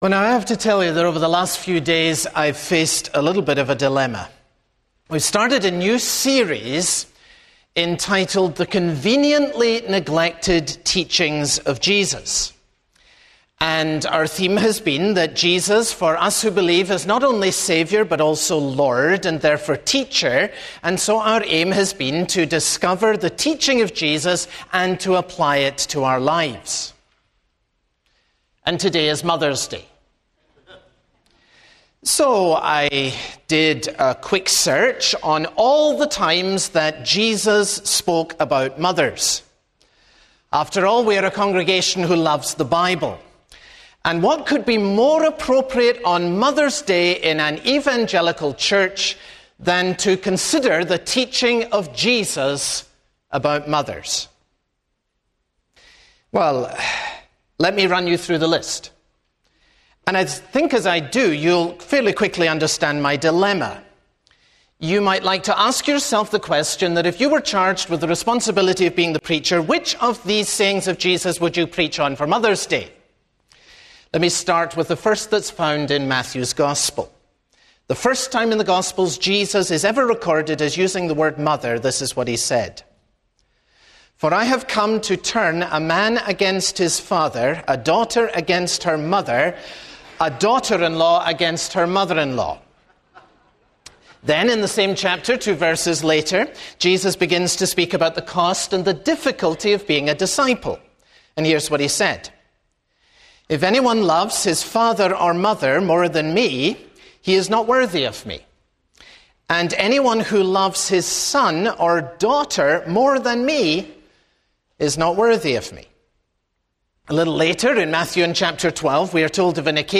Family (Sermon on Luke 8:19-21) - Open the Bible